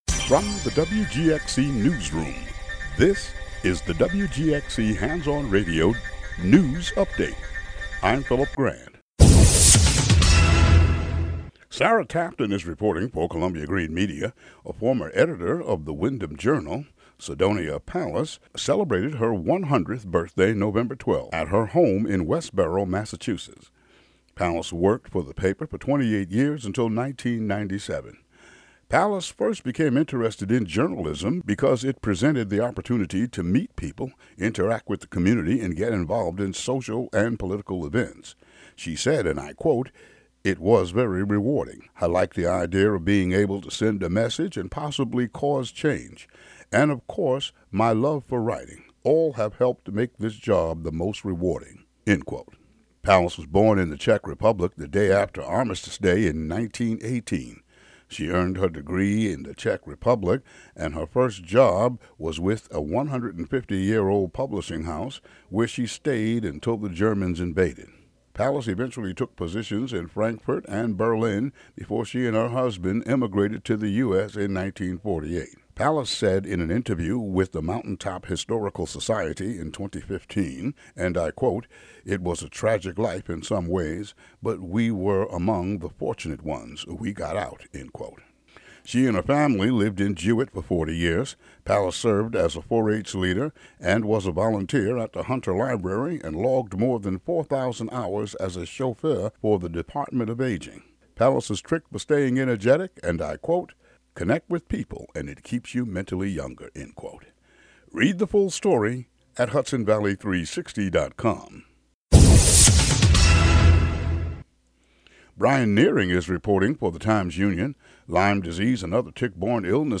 News update for the area.